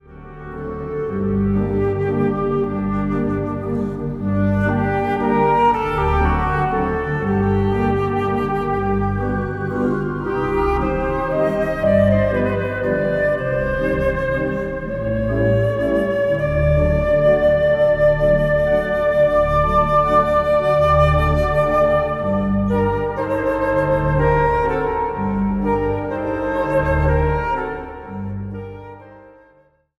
bekende romantische thema's en filmmuziek op orgel en fluit